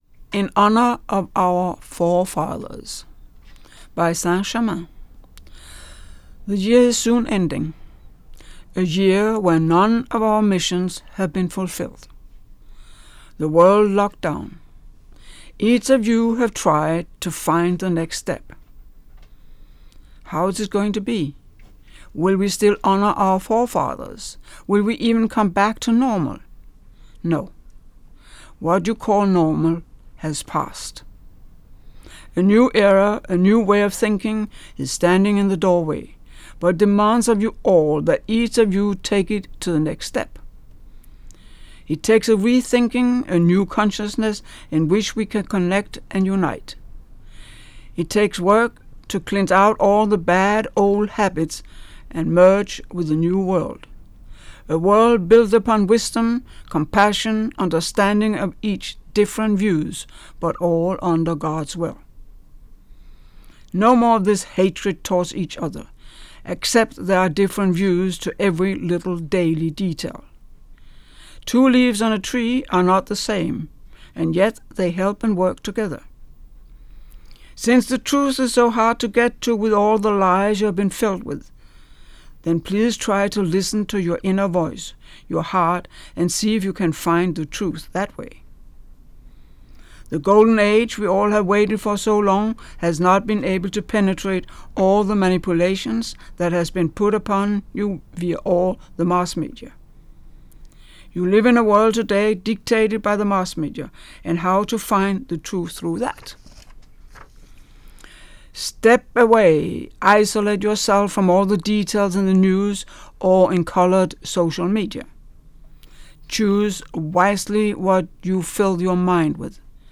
Spoken so clearly and precisely…the bottom line to all this mess!
There is strength in the sound of your voice.